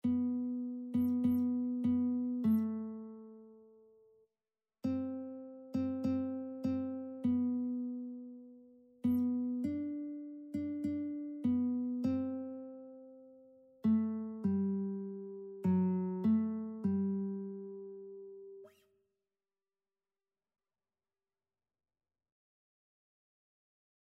Christian Christian Lead Sheets Sheet Music Hear Our Prayer, O Lord
G major (Sounding Pitch) (View more G major Music for Lead Sheets )
4/4 (View more 4/4 Music)
Classical (View more Classical Lead Sheets Music)